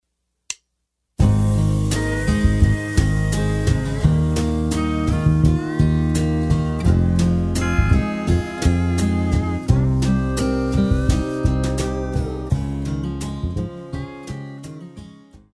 Classical
French Horn
Band
Etude,Course Material,Classical Music
Only backing